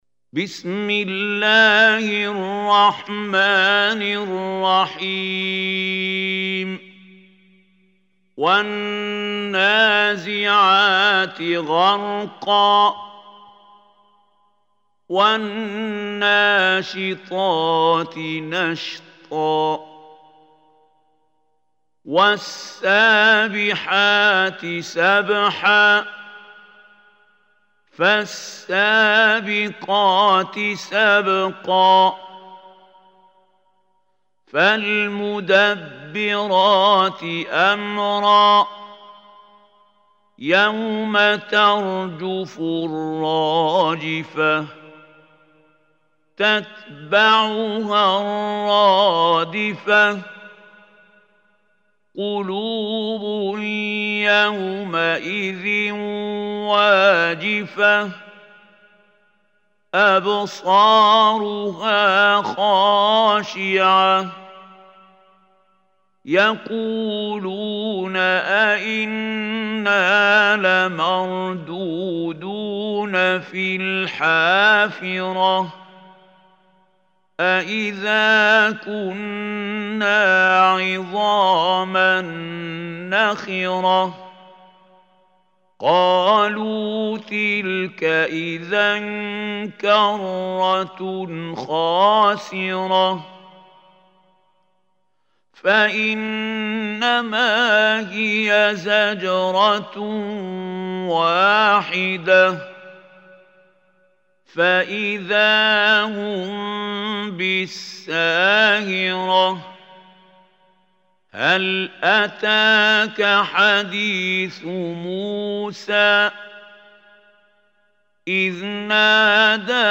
Surah An-Naziat MP3 Recitation By Khalil Hussary
Surah An-Naziat is 79 surah of Holy Quran. Listen or play online mp3 tilawat / recitation in Arabic in the beautiful voice of Sheikh Mahmoud Khalil Al Hussary.